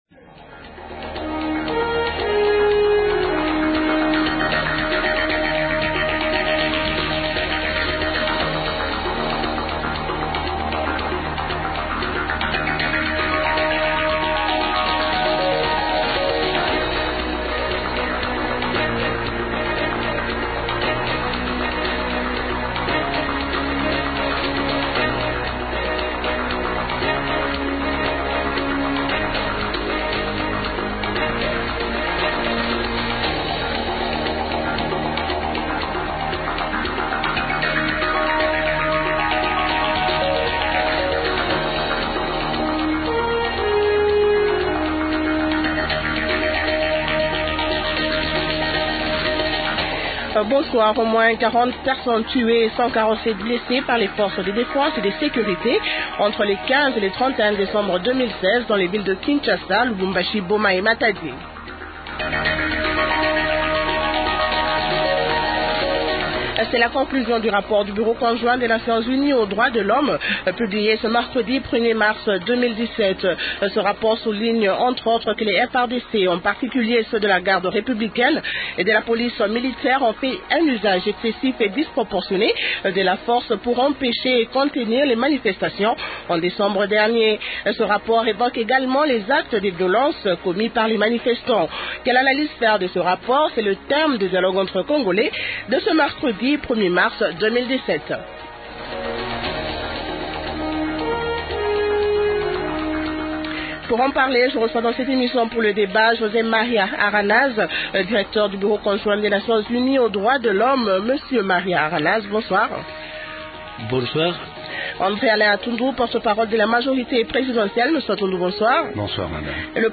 Invités -José Maria Aranaz, Directeur du Bureau conjoint des Nations Unies aux droits de l’Homme (Bcnudh). -André-Alain Atundu, Porte-parole de la Majorité présidentielle.